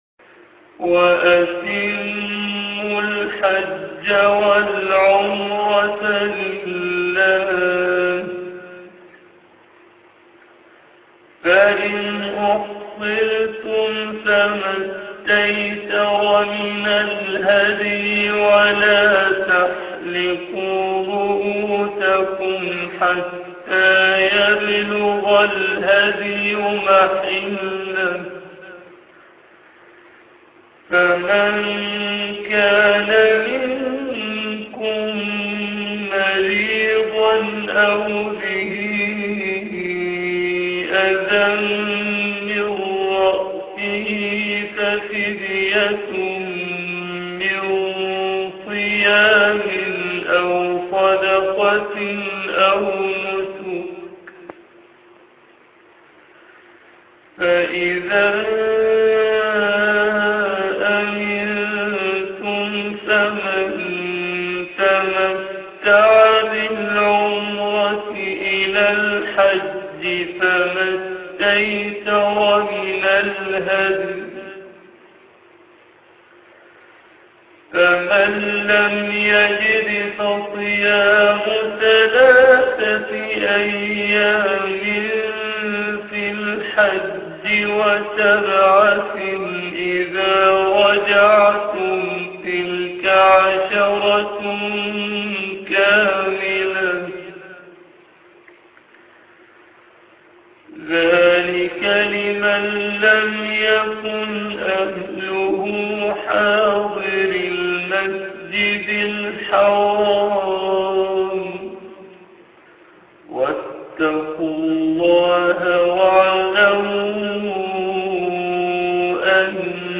عنوان المادة ايات الحج بصوت الشيخ أبي إسحاق الحويني طيب الله ثراه